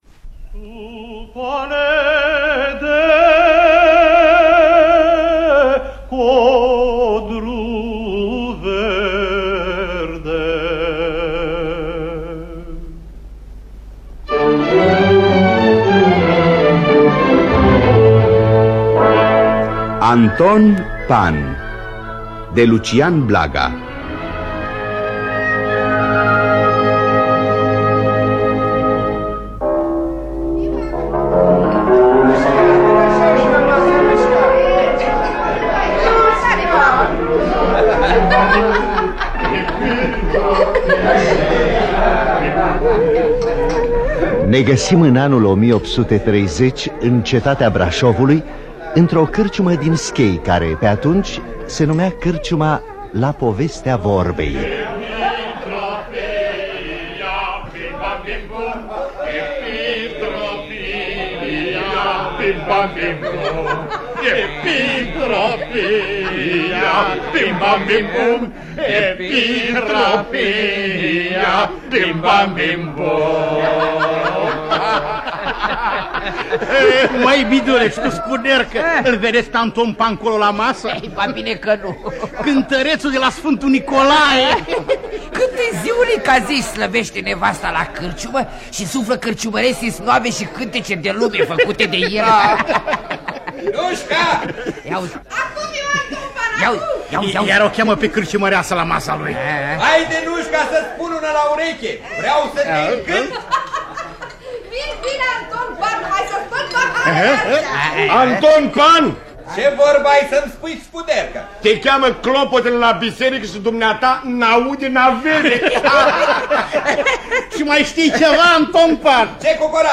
Adaptarea radiofonică de Dan Tărchilă.